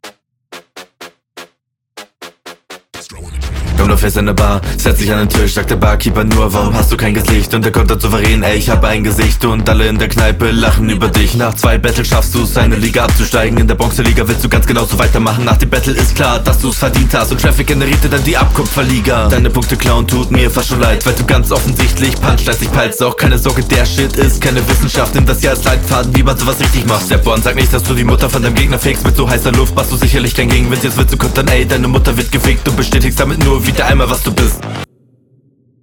Distro Beat ist schon ein Win in sich.
klingt wild. ab und zu wirkt das wieder etwas holprig aber ist durchaus souverän geflowt.